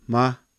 Ma - short vowel sound | 484_14,400